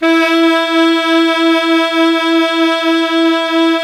SAX 2 ALTO02.wav